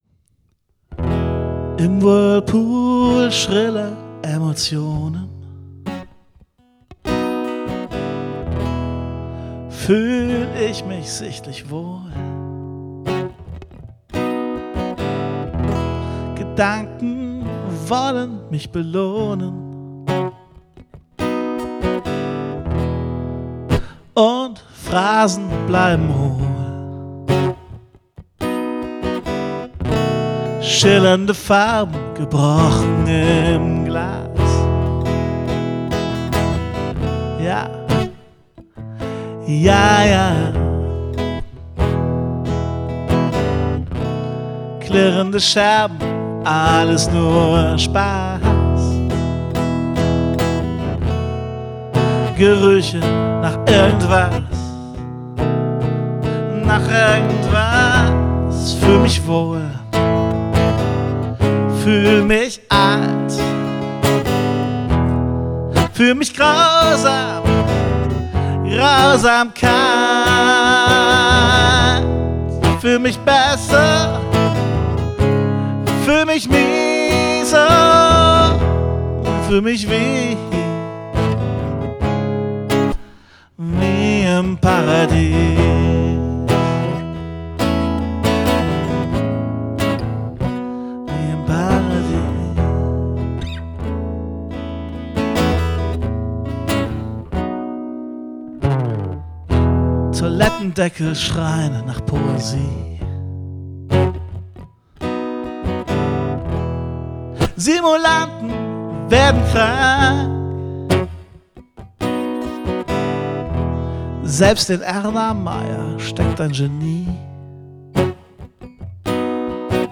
Aufgenommen und gemischt am 29. Juli 2017